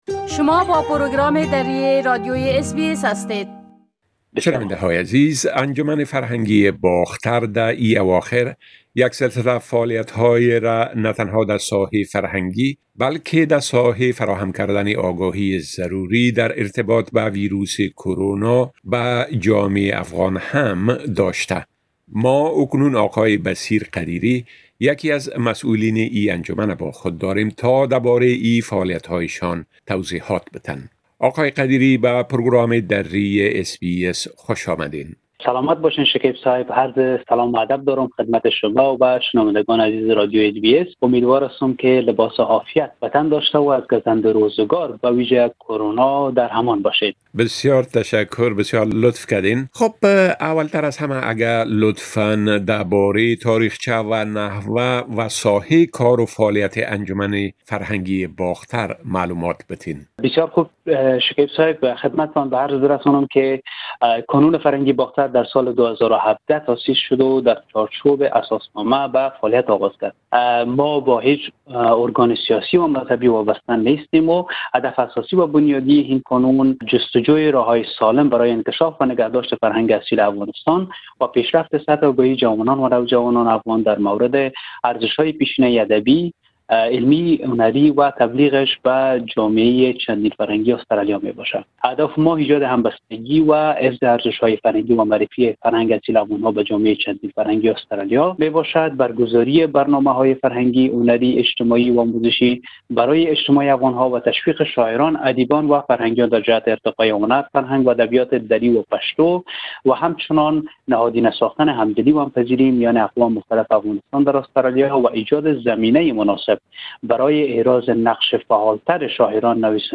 در صحبتى با برنامۀ درى راديوى اس بى اس